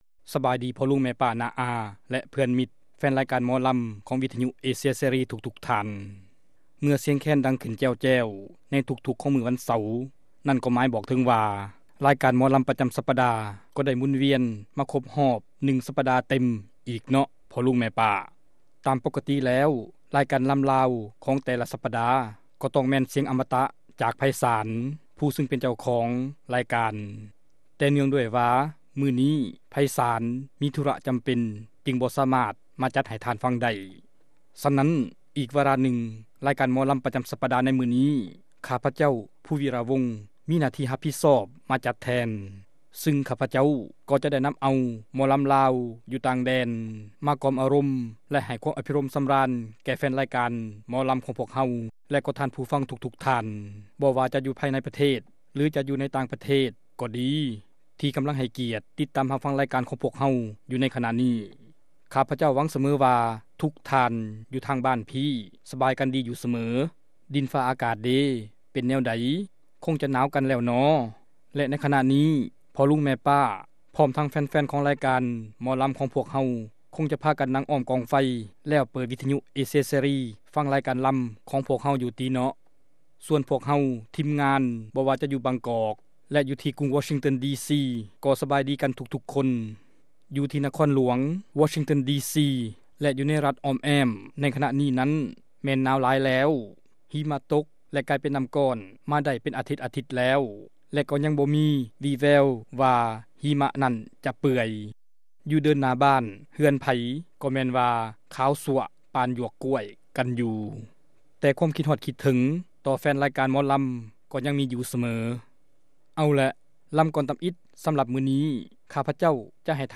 ຣາຍການໜໍລຳ ປະຈຳສັປະດາ ວັນທີ 16 ເດືອນ ທັນວາ ປີ 2005